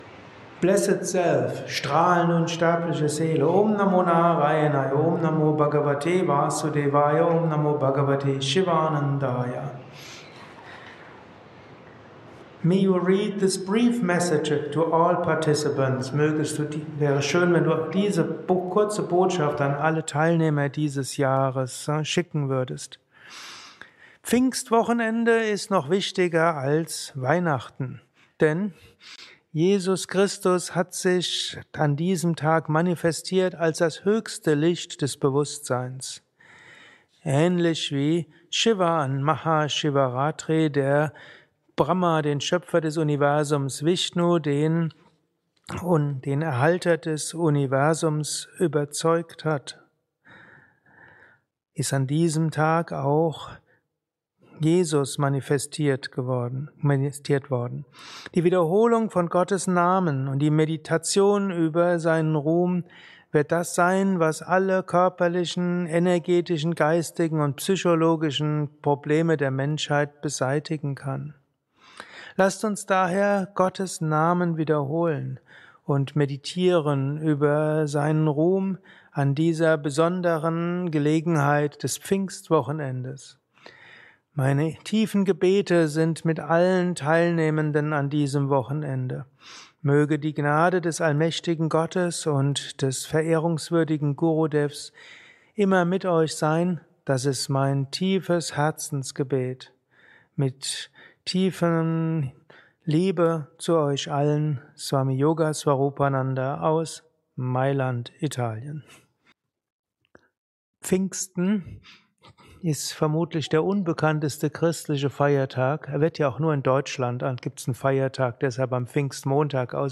Satsangs gehalten nach einer Meditation im Yoga Vidya Ashram Bad